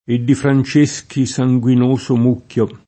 francesco [fran©%Sko] etn.; pl. m. ‑schi — voce ant. per «francese»: E di Franceschi sanguinoso mucchio [